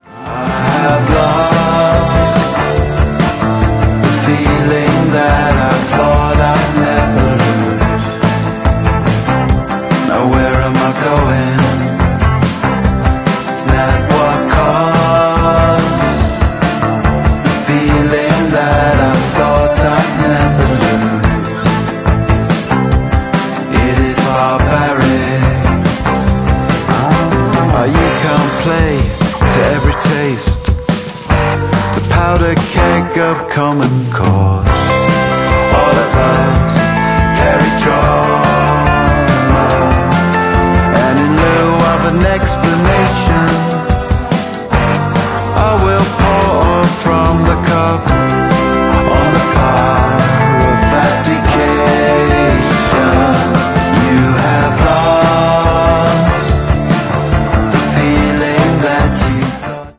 BASS edition.